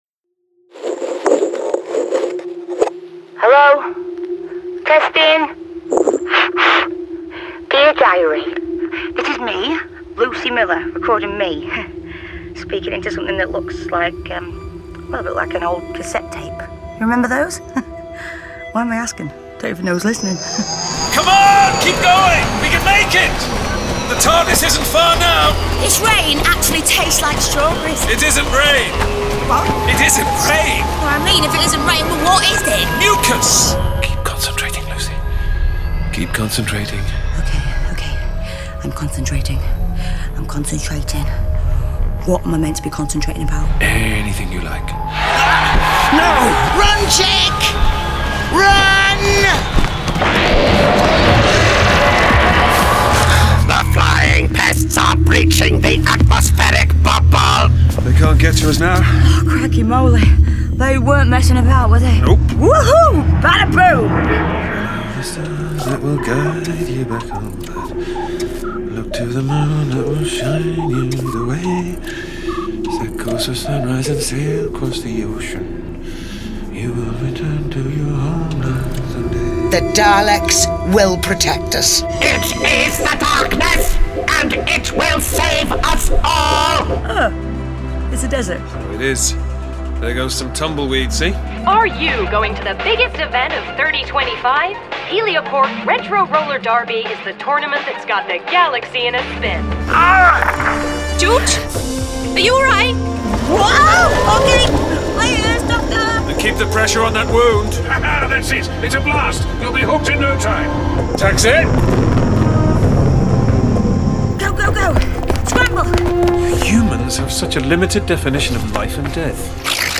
Starring Paul McGann Sheridan Smith